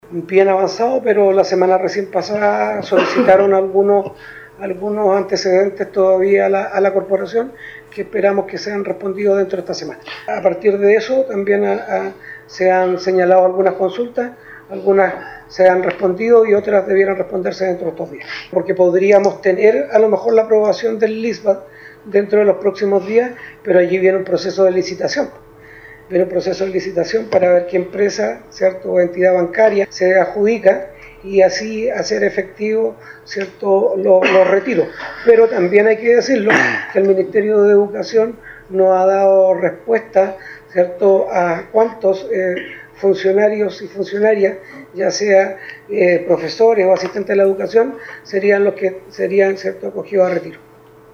También ante una consulta, el jefe comunal indicó que los trámites para poder obtener un Leaseback se encuentran avanzados, sin embargo aún deben responderse varias observaciones realizadas dentro del proceso.